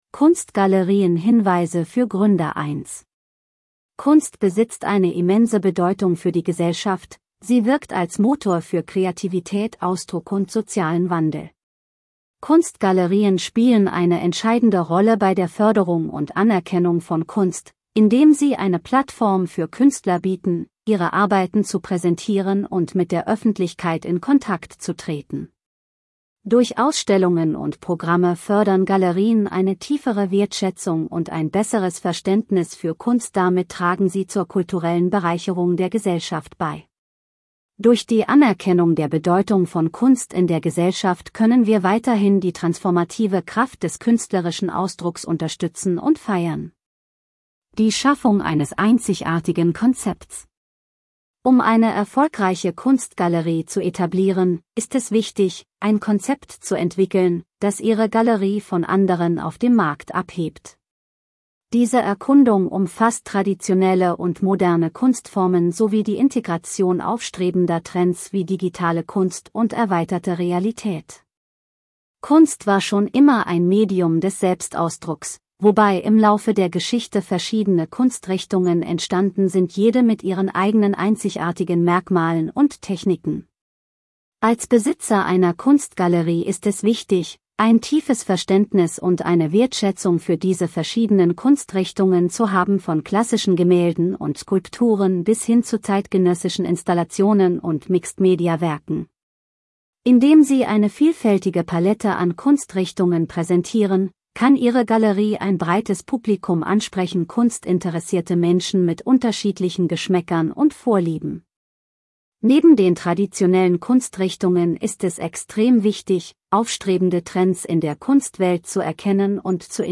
Text gelesen von der KI-Stimme Louisa